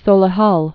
(sōlĭ-hŭl)